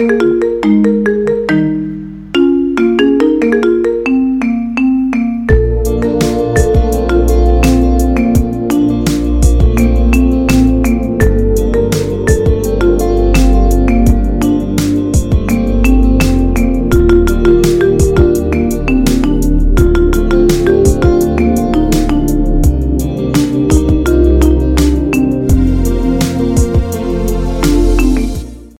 หมวดหมู่: เสียงเรียกเข้า